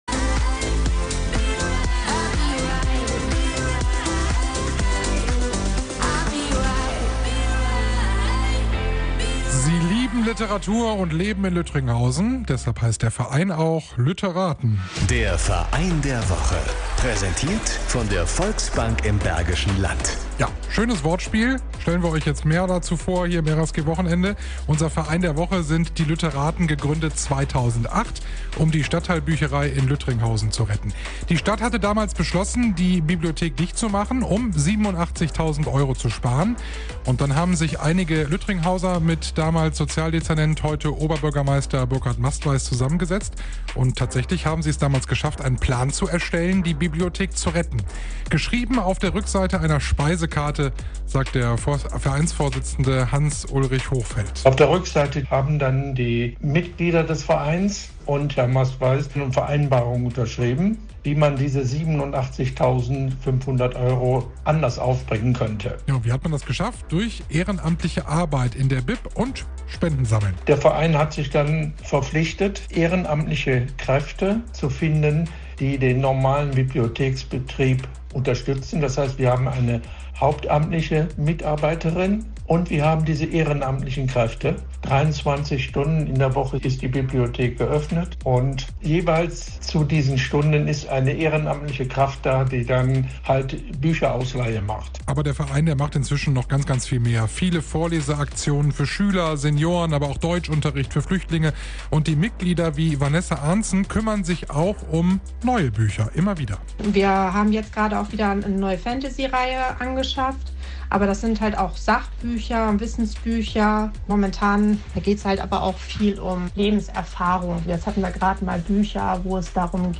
„Verein der Woche“ Teil 3 Live Mittschnitt vom 12.02.22 bei Radio RSG